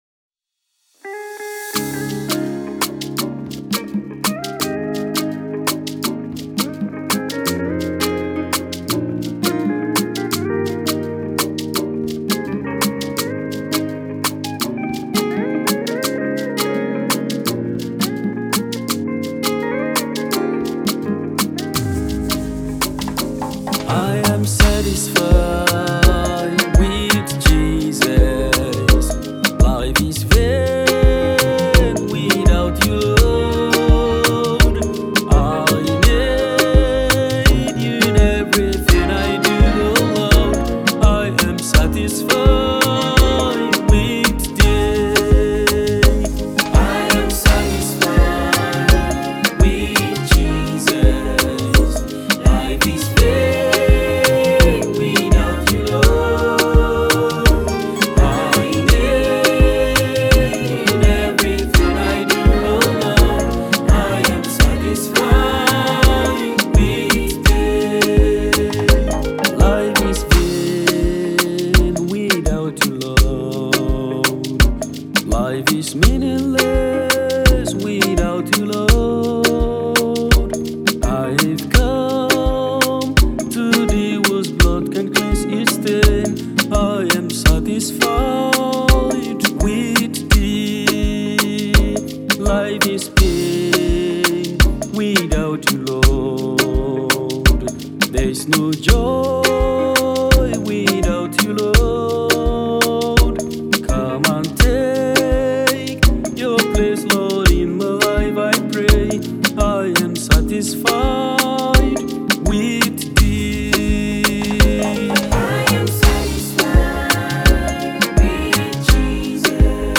Nigerian gospel singer and a songwriter